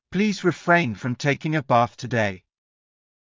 ﾌﾟﾘｰｽﾞ ﾘﾌﾚｲﾝ ﾌﾛﾑ ﾃｲｷﾝｸﾞ ｱ ﾊﾞｽ ﾄｩﾃﾞｨ